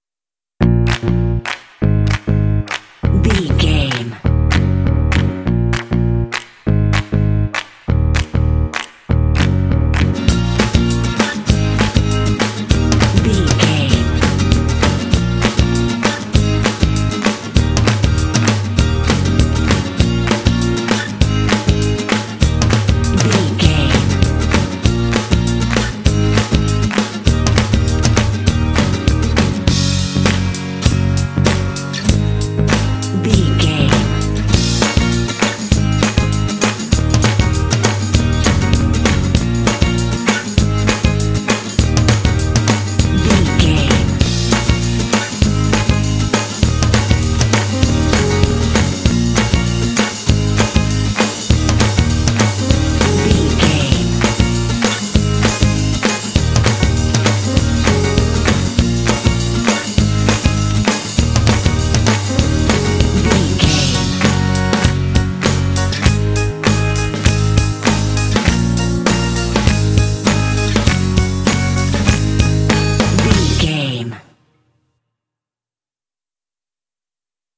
Uplifting
Aeolian/Minor
E♭
bright
joyful
piano
percussion
bass guitar
acoustic guitar
drums
electric organ
alternative rock